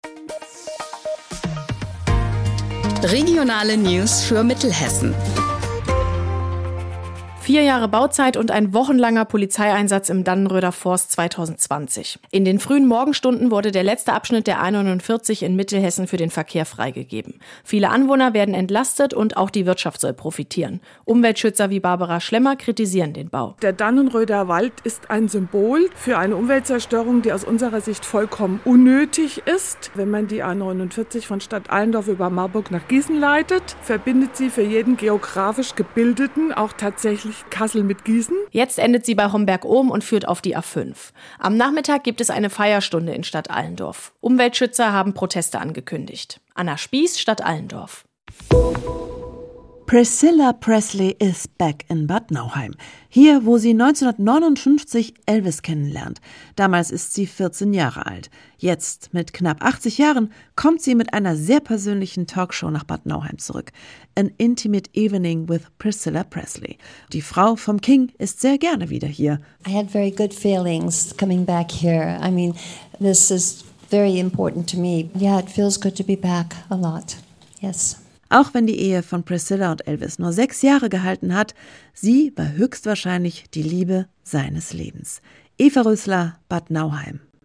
Regionale News für Mittelhessen Author: hessenschau Language: de-de Genres: Daily News , News Contact email: Get it Feed URL: Get it iTunes ID: Get it Get all podcast data Listen Now...